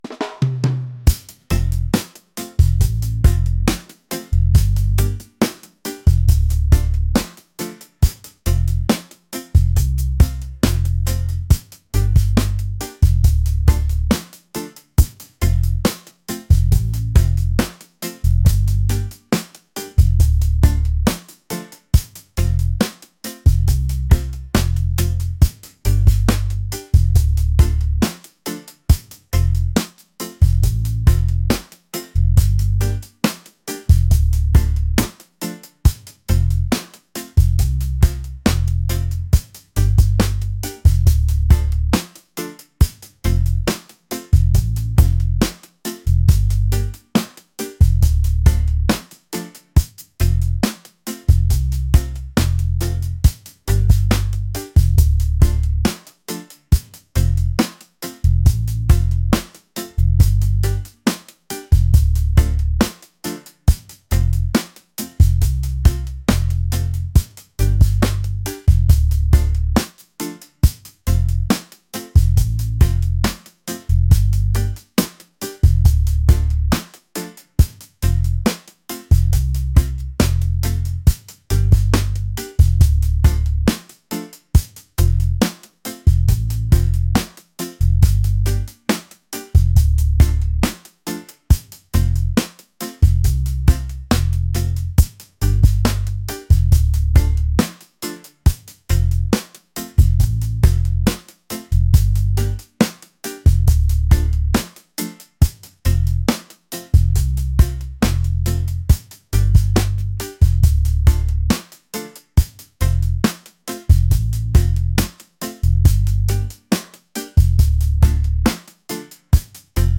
laid-back | reggae